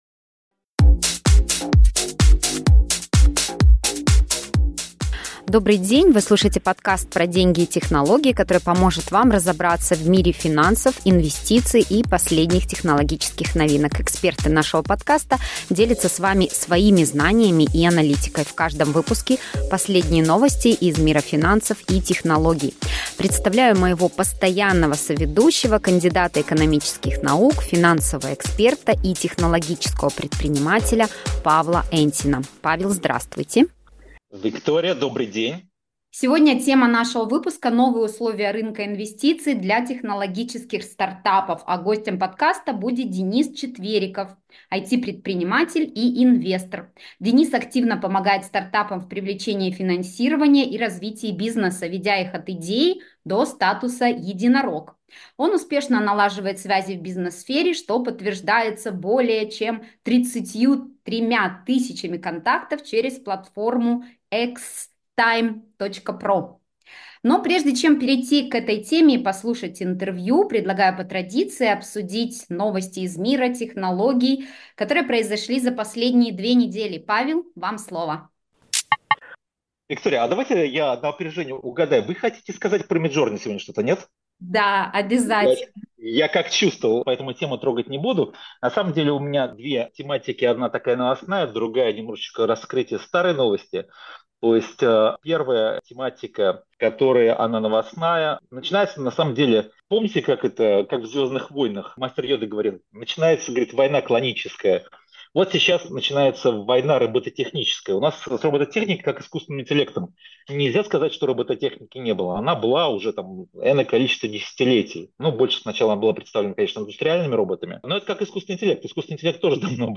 Our guests share their knowledge and analysis with you. Each episode contains the latest news from the world of finance and technology.